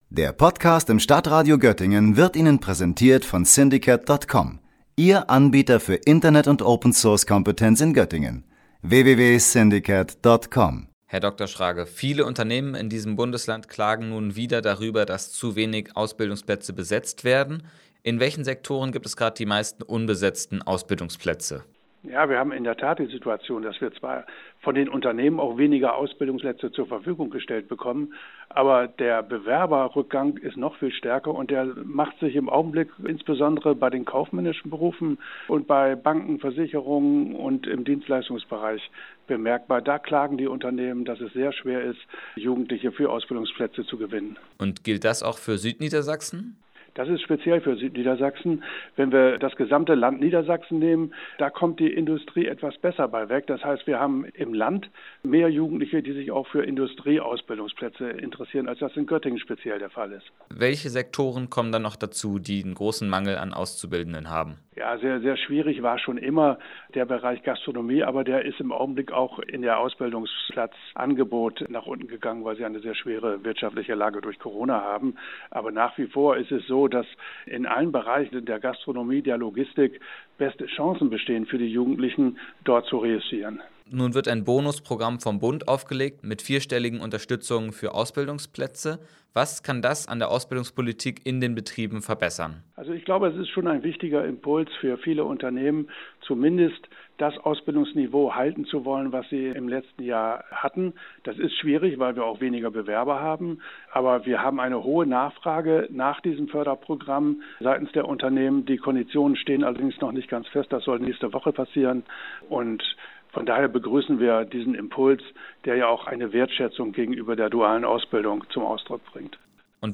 Außerdem geht es in dem Gespräch darum, was die Vorteile einer Ausbildung sind.